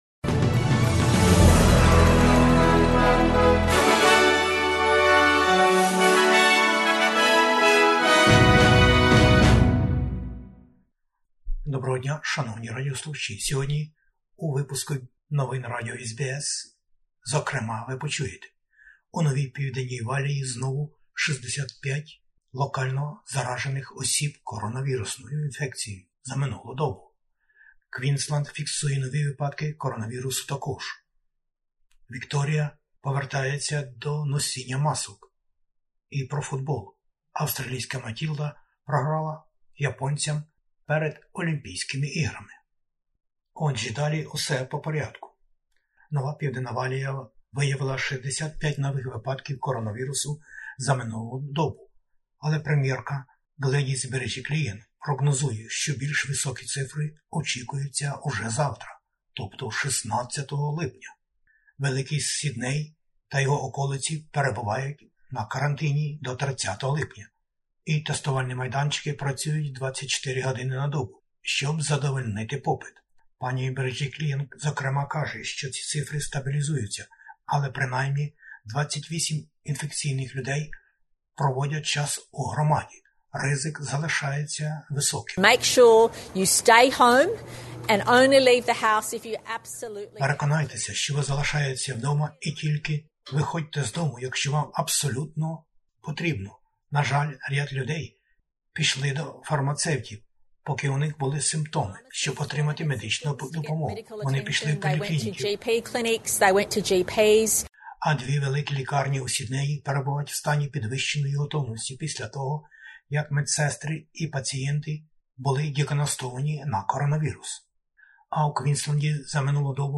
SBS новини українською